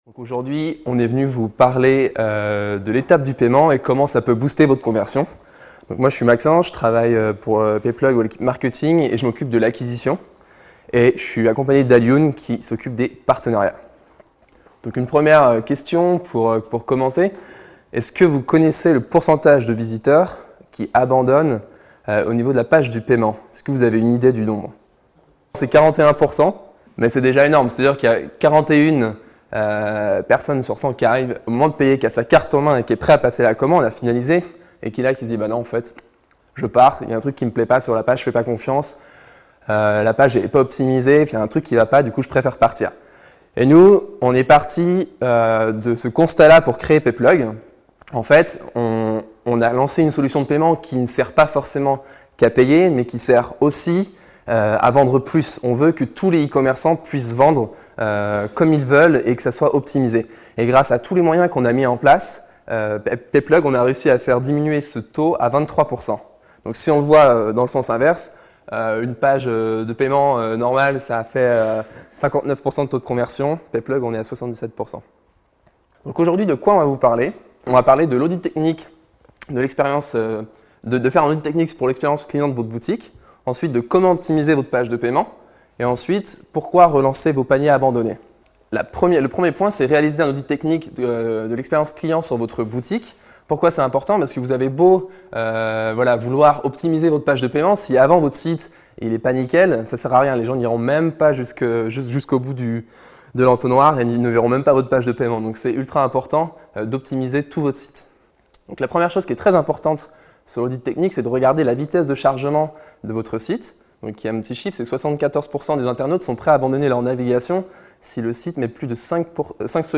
PayPlug anime une conférence pour vous aider à optimiser votre taux de conversion : Analyse & impact du paiement sur l'expérience client, l'importance de la relance des paniers abandonnés, ...